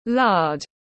Mỡ lợn tiếng anh gọi là lard, phiên âm tiếng anh đọc là /lɑːd/
Lard /lɑːd/